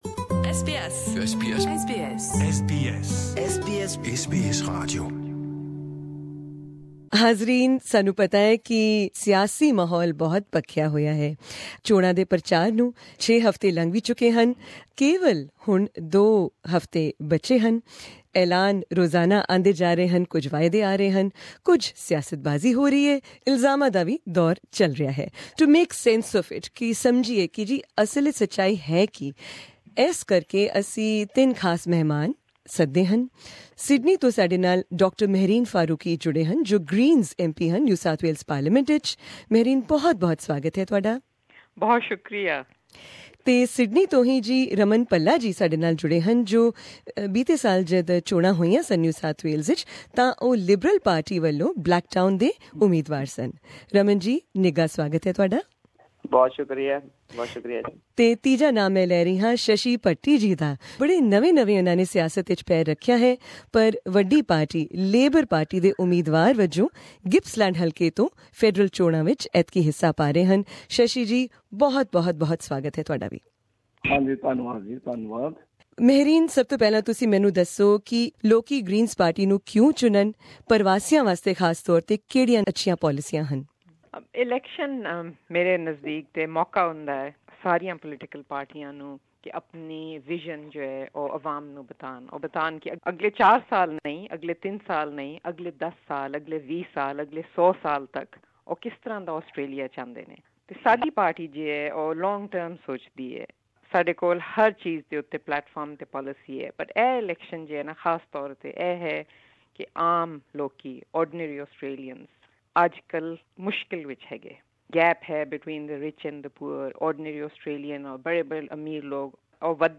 These are just a few of the questions we put to members of the above three parties, in a robust panel discussion organised by SBS Punjabi this week.